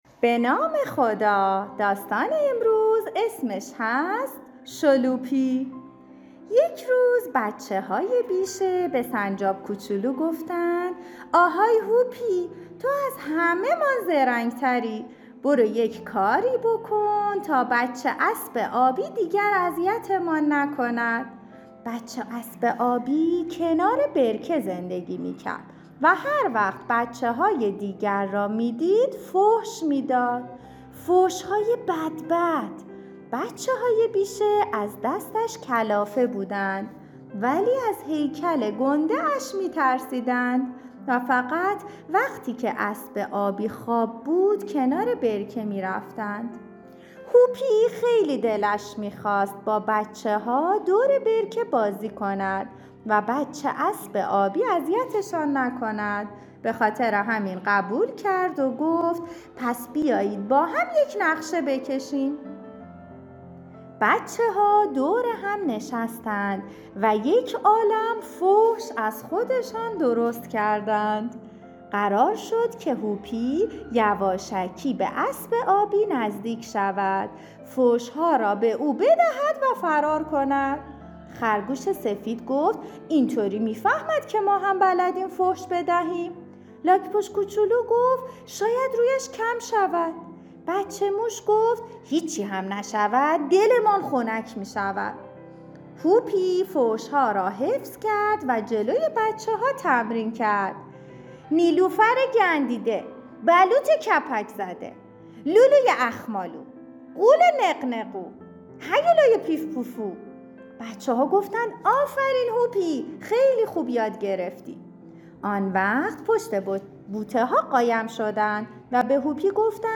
از شما دعوت می‌کنیم تا در تعطیلات نوروزی از کتاب های صوتی کتابخانه استفاده کنید.